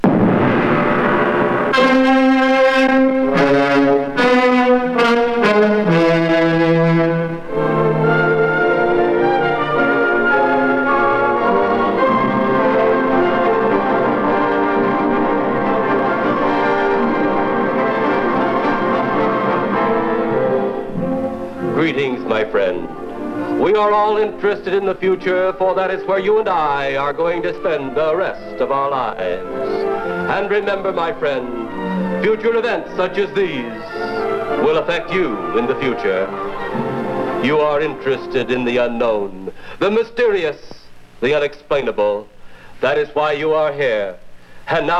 Stage & Screen, Soundtrack　USA　12inchレコード　33rpm　Mono